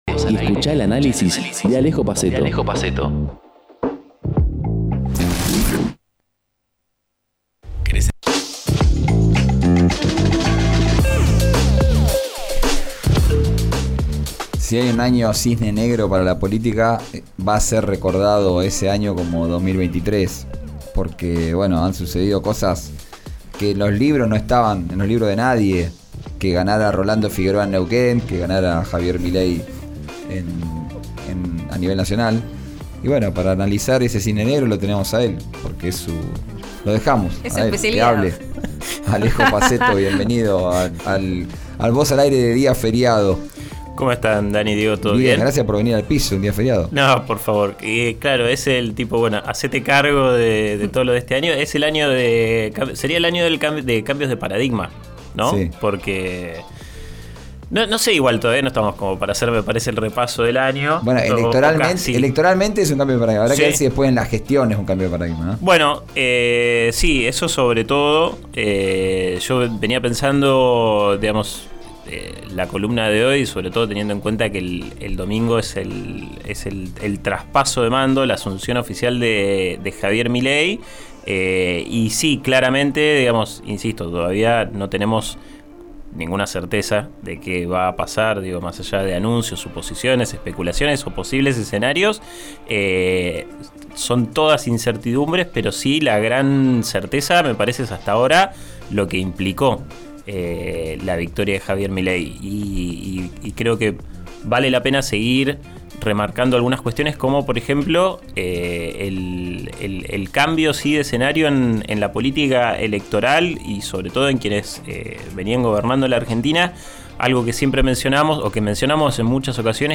Nueva columna en RÍO NEGRO RADIO